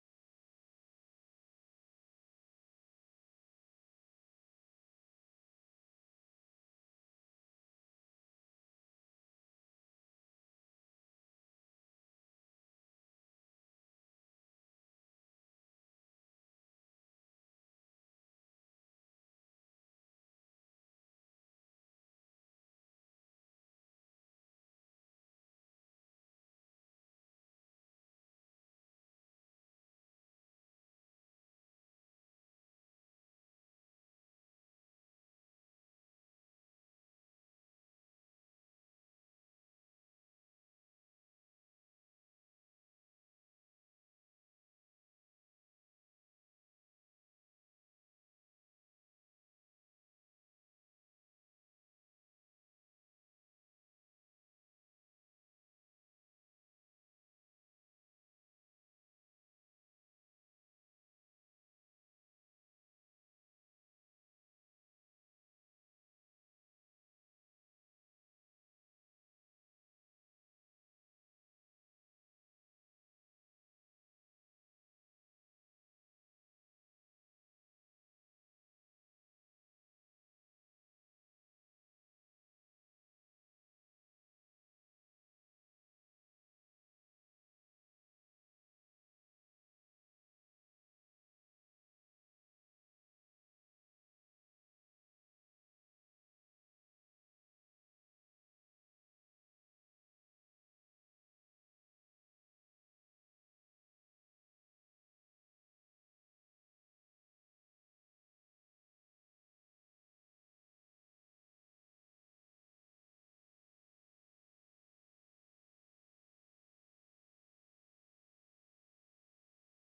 January 2, 2022 (Morning Worship)
During the service we will celebrate communion.